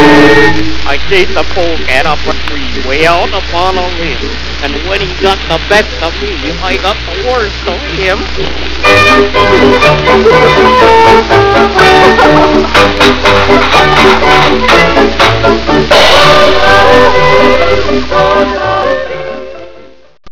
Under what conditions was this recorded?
an old 78 RPM record